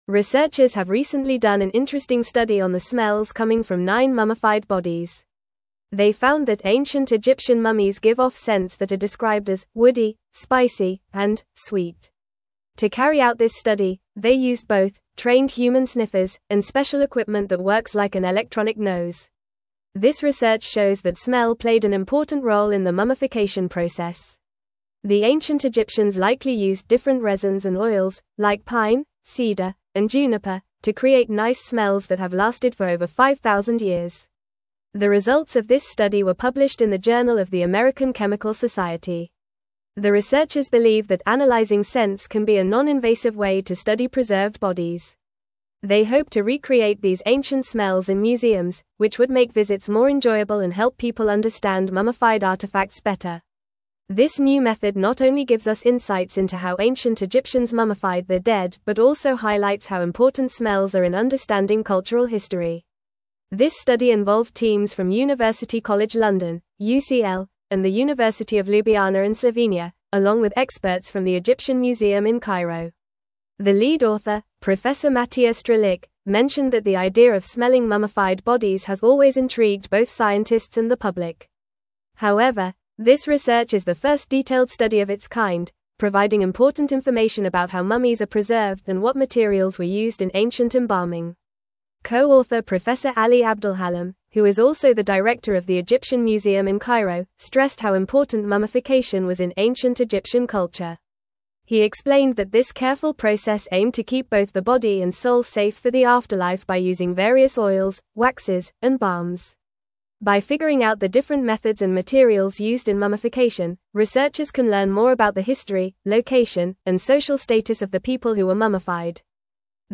Fast
English-Newsroom-Upper-Intermediate-FAST-Reading-Ancient-Egyptian-Mummies-Emit-Unique-Scents-Study-Revealed.mp3